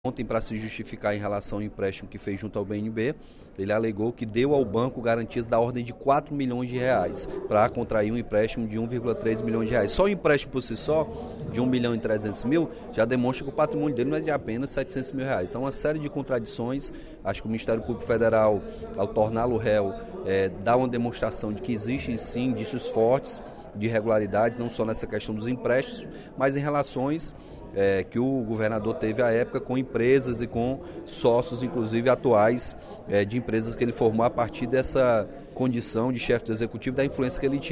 O deputado Capitão Wagner (PR) defendeu, durante o primeiro expediente da sessão plenária desta quarta-feira (07/06), uma apuração rigorosa da denúncia de que o ex-governador Cid Gomes teria cometido crime contra o Sistema Financeiro Nacional na construção de galpões no município de Sobral.